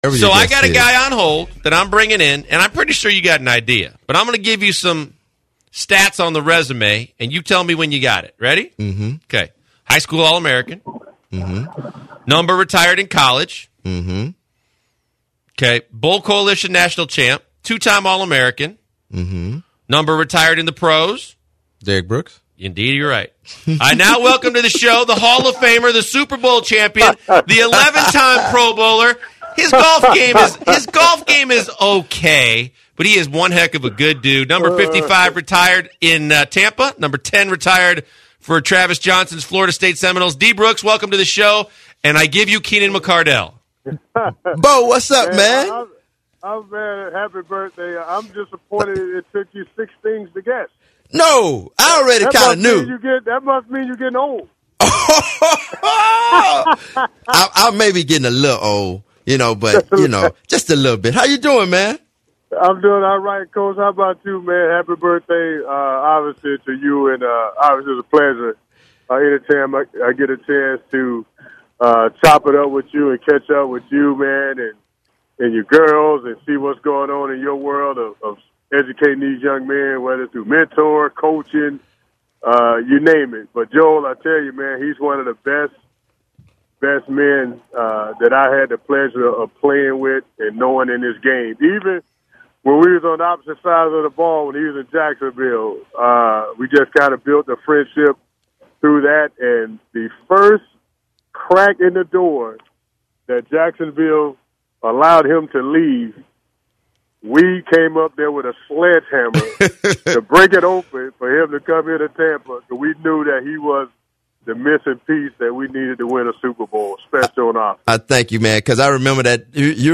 Derrick Brooks Interview